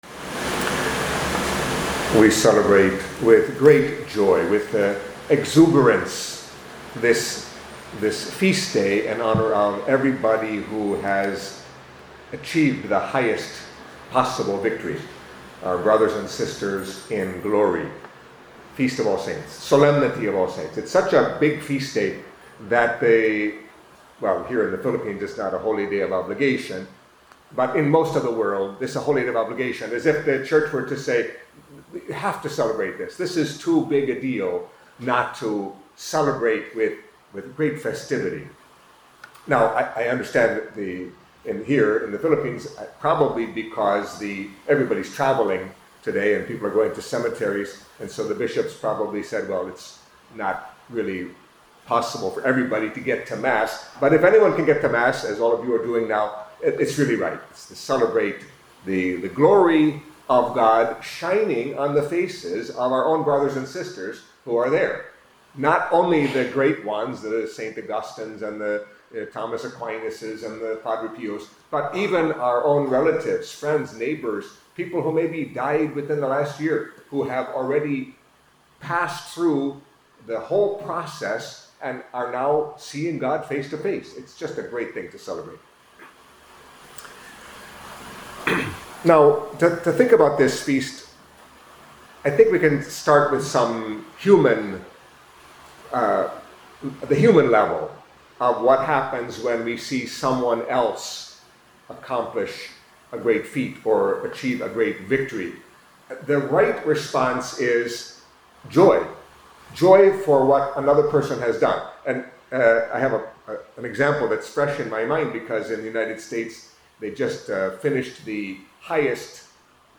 Catholic Mass homily for Solemnity of All Saints